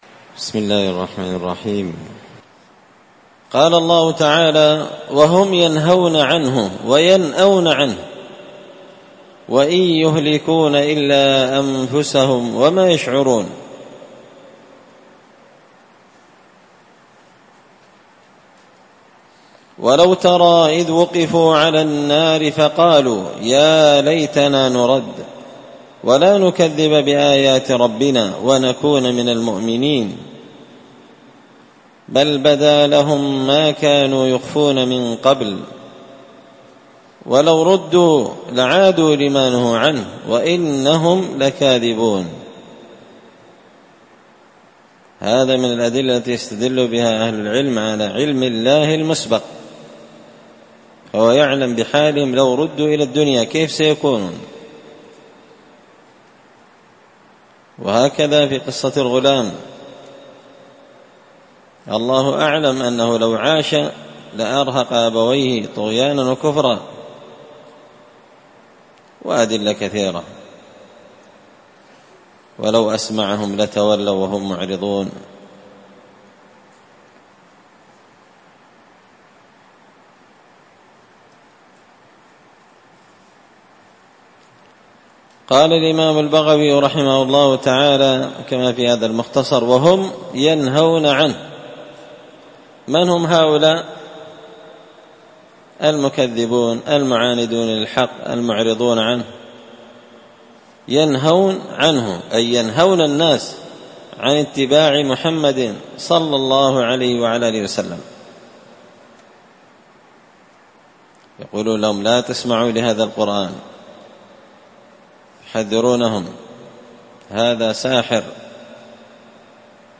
مختصر تفسير الإمام البغوي رحمه الله الدرس 302